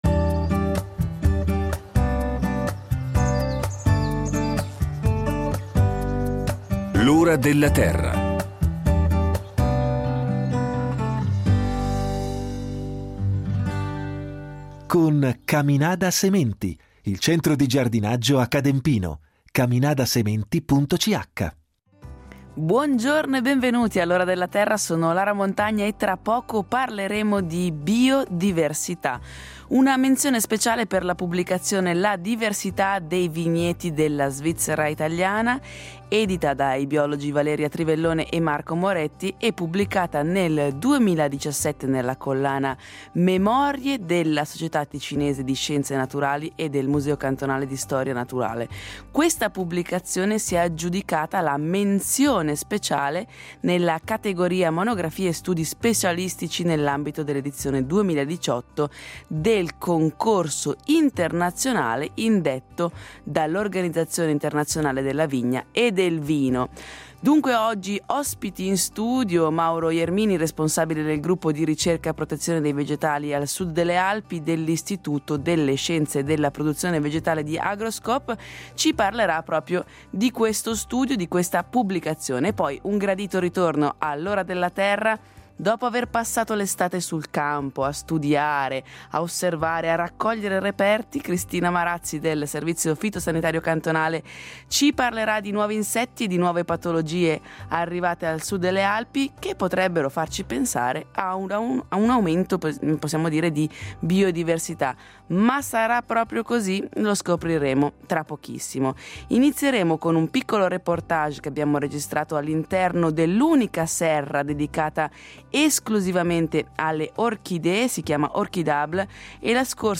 In studio con noi